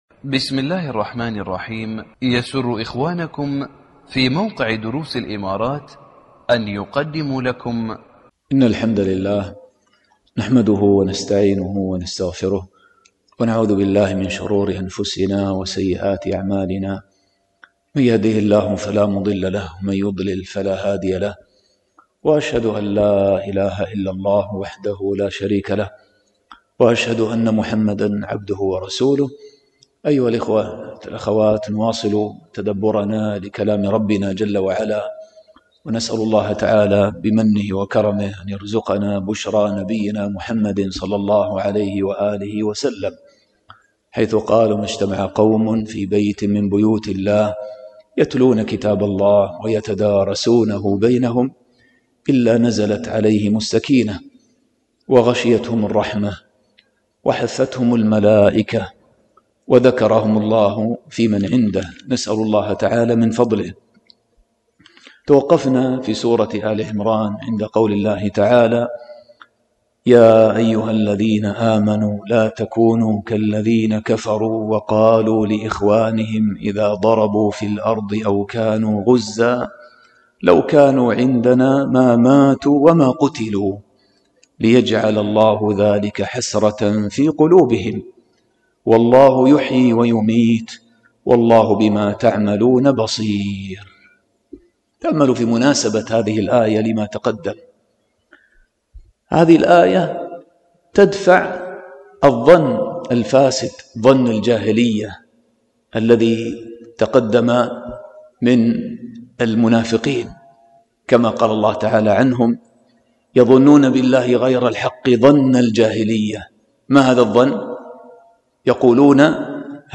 الدرس 69 – من الآية 281 إلى 282